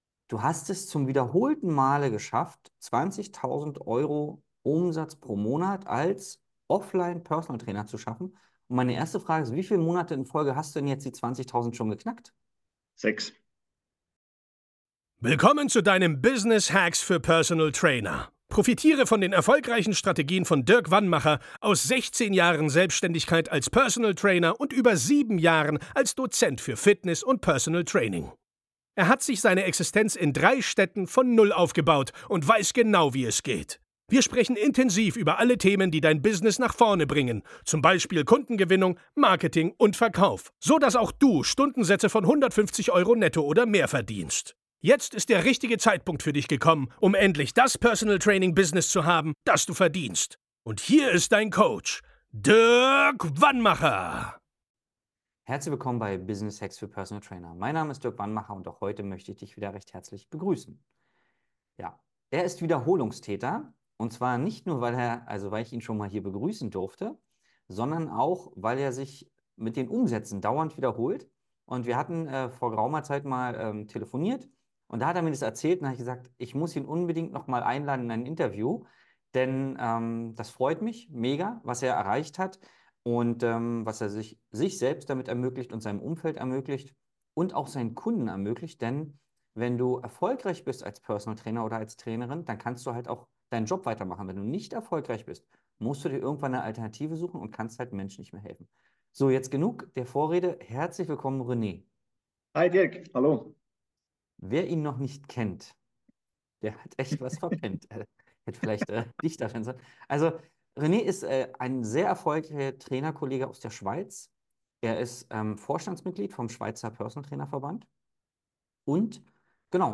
ein Kundeninterview